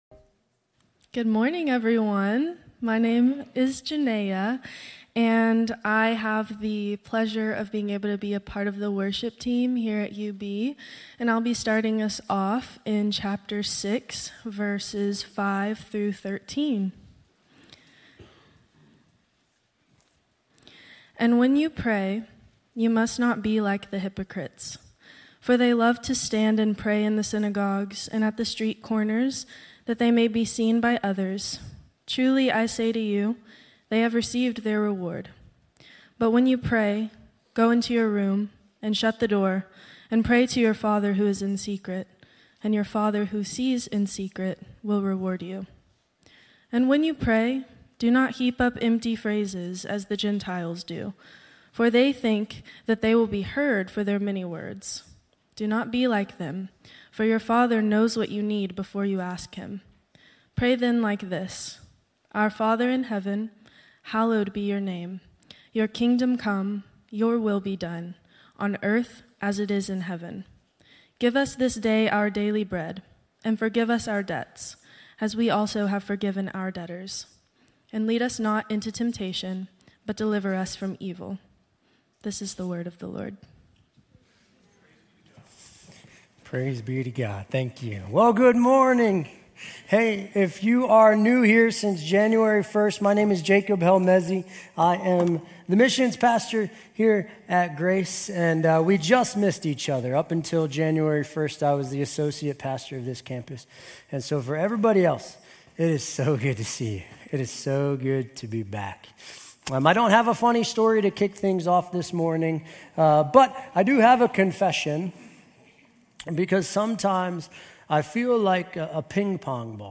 Grace Community Church University Blvd Campus Sermons 3_9 University Blvd Campus Mar 10 2025 | 00:27:44 1x 00:00 / 00:27:44 Subscribe Share RSS Feed Share Link Embed